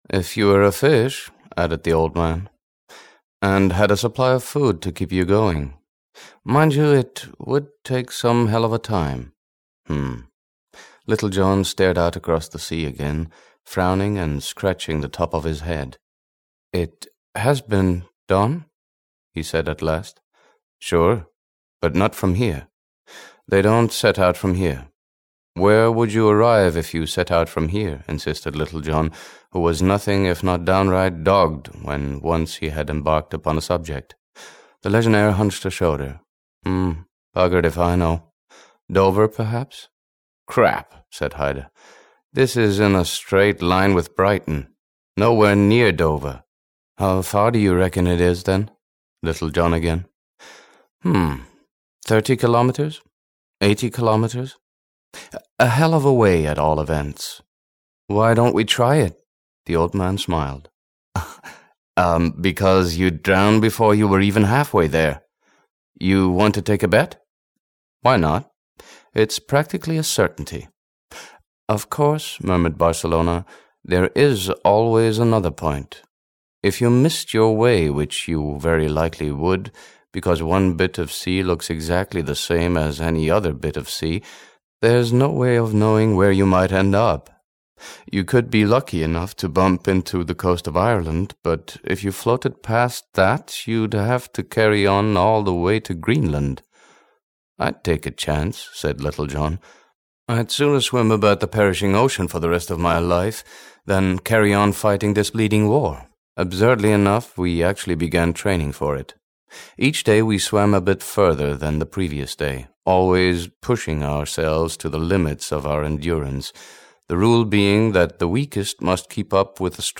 Liquidate Paris (EN) audiokniha
Ukázka z knihy